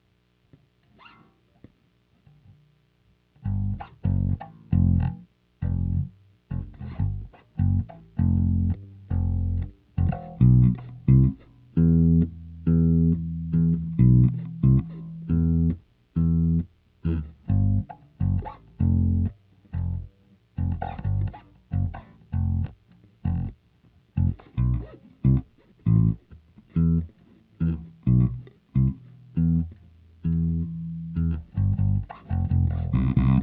Bass_023.wav